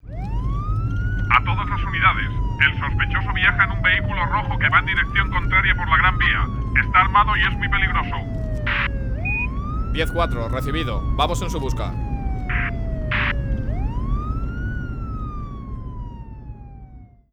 Interior de coche de policía con radio
Sonidos: Transportes
Sonidos: Ciudad